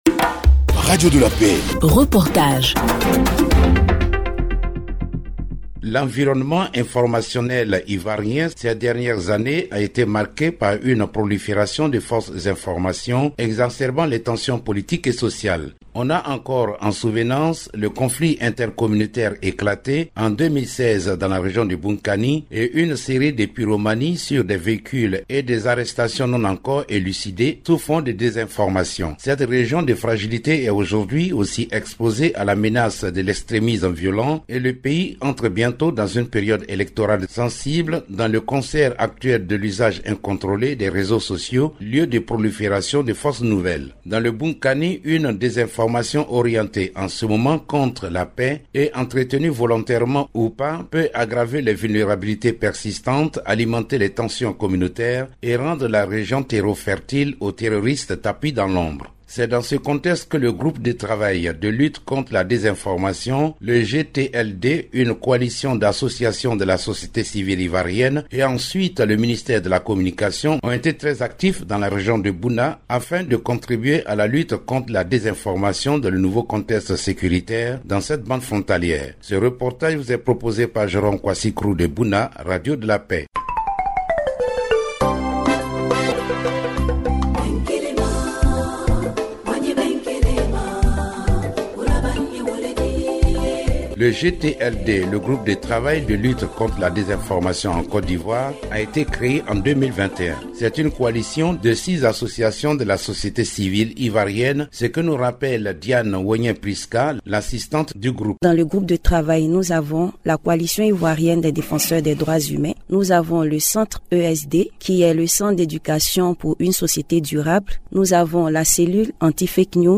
Reportage – L’impact de la désinformation dans les communautés à Bouna - Site Officiel de Radio de la Paix